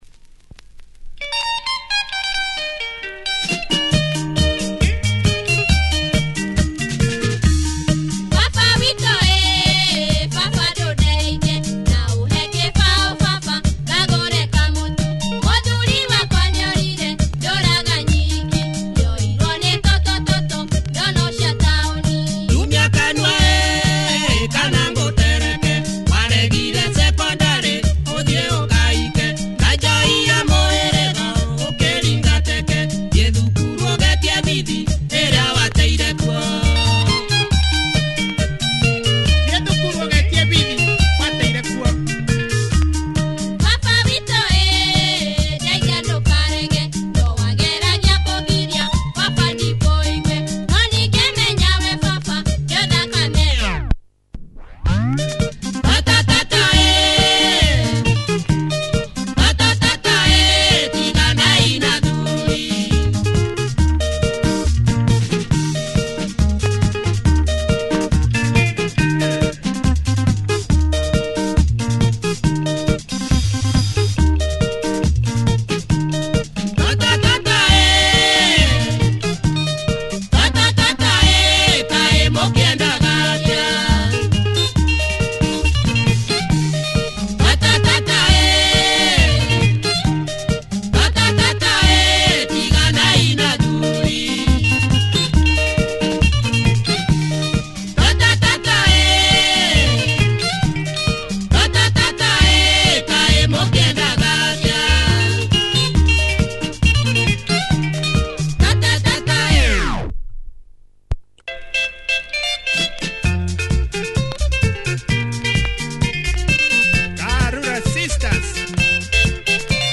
Female Kikuyu benga groove, club friendly!